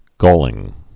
(gôlĭng)